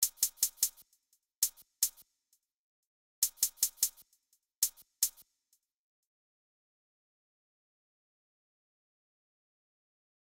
Closed Hats
Beast_Hi Hat.wav